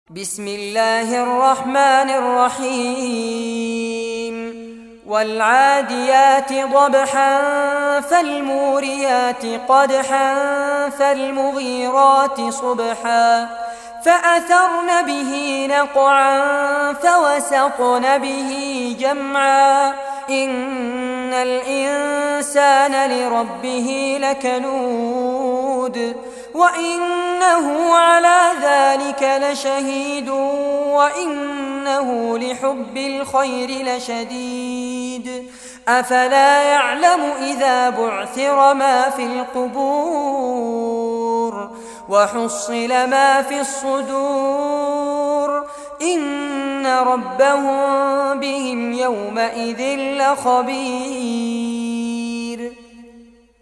Surah Al-Adiyat Recitation by Fares Abbad
Surah Al-Adiyat, listen or play online mp3 tilawat / recitation in Arabic in the beautiful voice of Sheikh Fares Abbad.